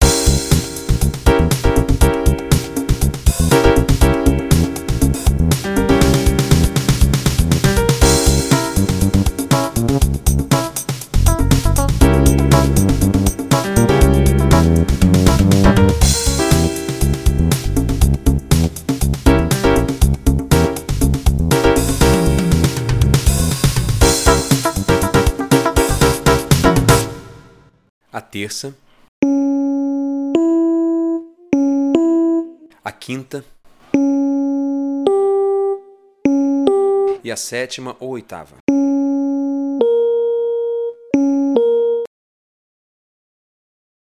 Você vai ouvir o acorde tocado por uma banda e, em seguida, nó vamos desmembrá-lo nas notas que o compôe.